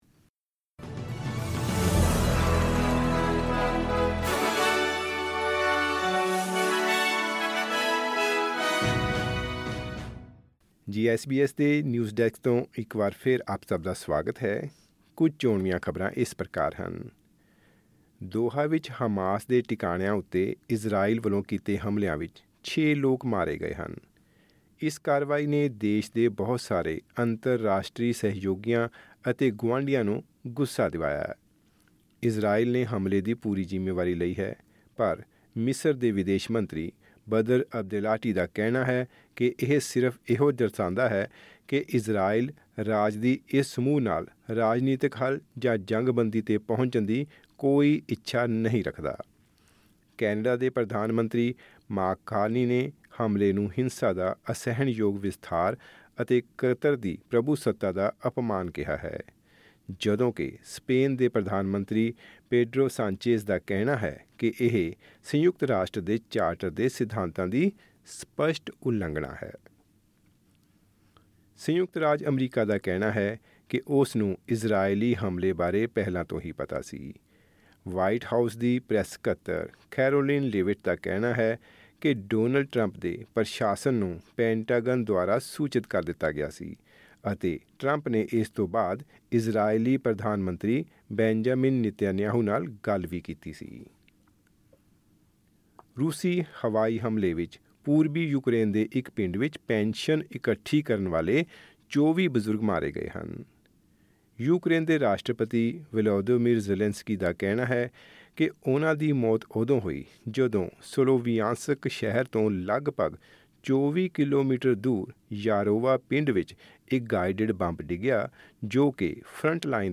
ਖ਼ਬਰਨਾਮਾ: ਪਹਿਲੇ ਘਰ ਖਰੀਦਦਾਰਾਂ ਲਈ ਵਧੇਰੇ ਵਿਕਲਪ, ਪਰ ਕੁਝ ਬਾਜ਼ਾਰ ਫਿਰ ਵੀ ਪਹੁੰਚ ਤੋਂ ਦੂਰ